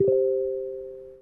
newmsg.wav